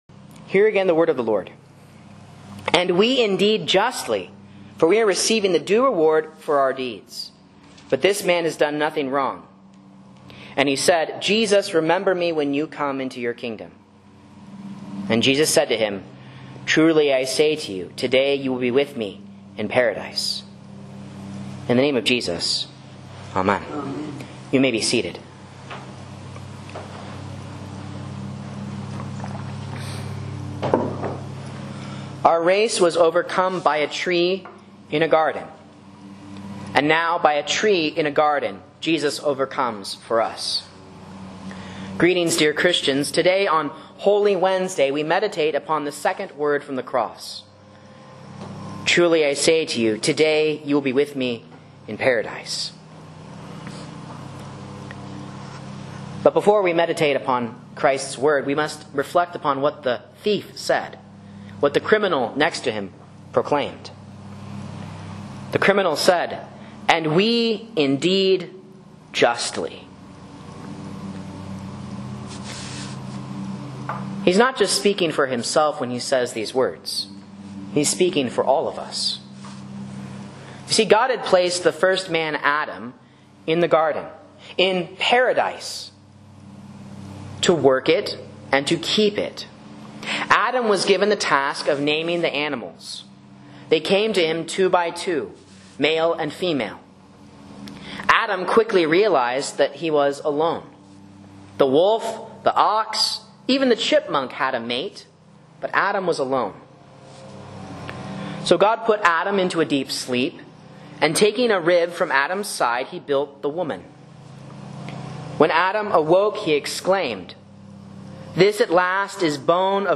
A Sermon on Luke 23:41-43 for Holy Wednesday